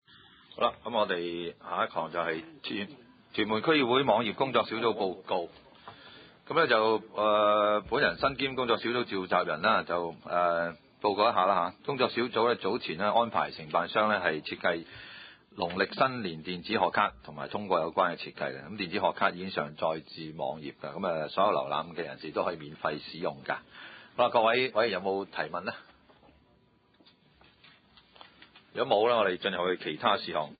屯門區議會會議室